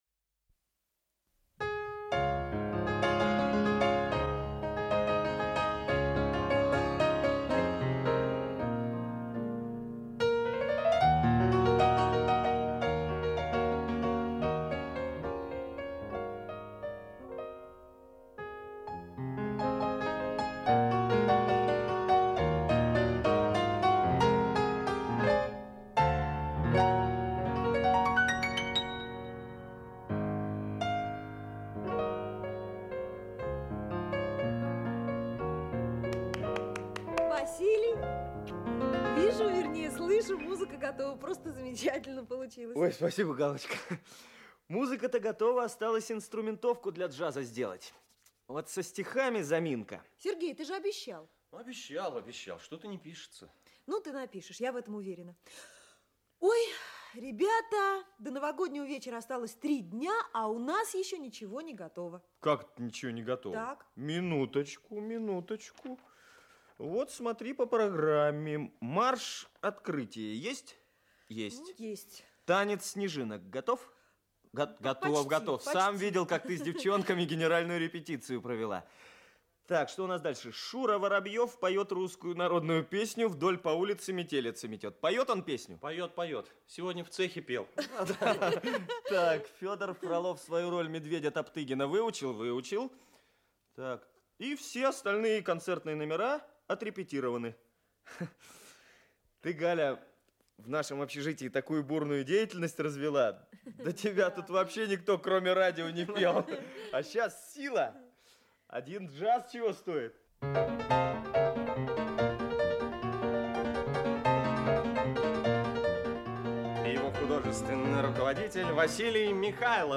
Аудиокнига Сюрприз для Снегурочки | Библиотека аудиокниг
Aудиокнига Сюрприз для Снегурочки Автор Евгений Крохмаль Читает аудиокнигу Актерский коллектив.